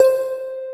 WATERHARP LM 2.wav